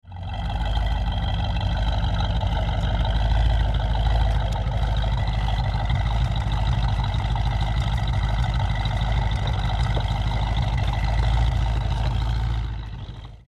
Klingeltöne
Thunderbird_sound3.mp3